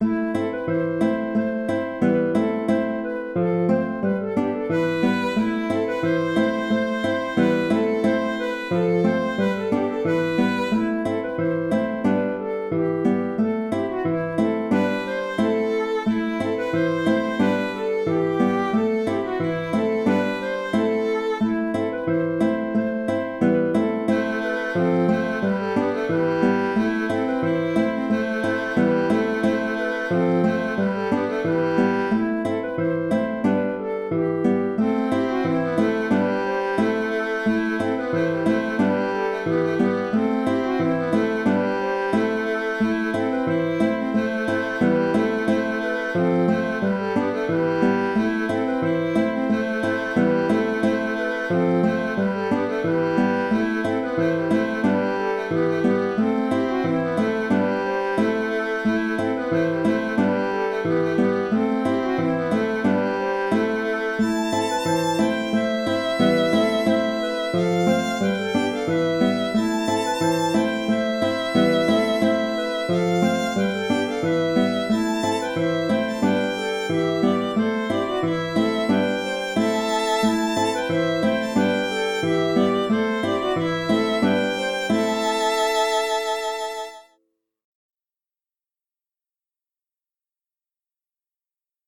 An Anglezed Bonetoù Ruz (Kas a bahr) - Musique bretonne
Je présente deux contrechants. Le premier est sous la ligne mélodique du thème et je propose de le jouer de préférence aux reprises. Le deuxième est au-dessus du thème et je propose de ne le jouer que vers la fin du morceau..